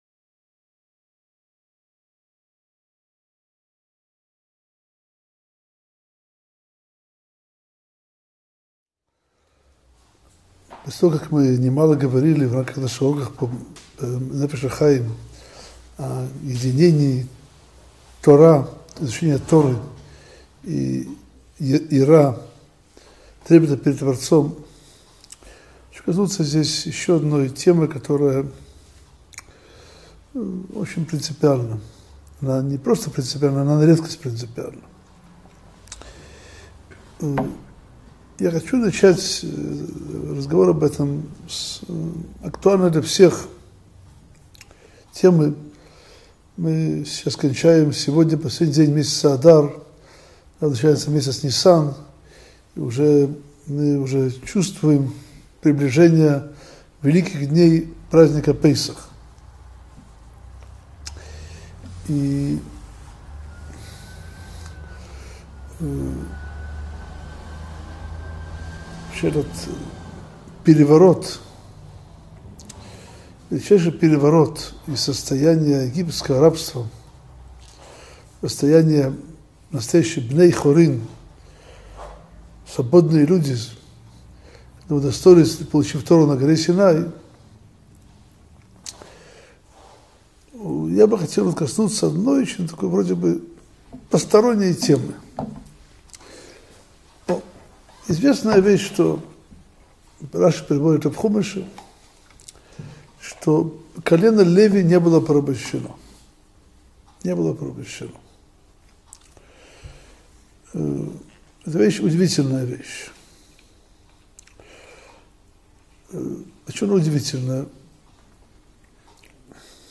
Нефеш аХаим - Урок 27 - Почитание Торы - Сайт о Торе, иудаизме и евреях